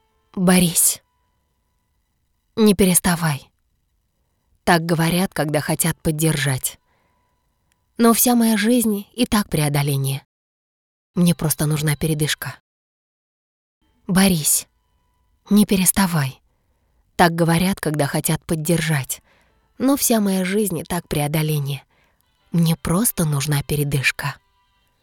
• Голос: Сопрано
• Женский
• Высокий
Закадровый текст - Душевно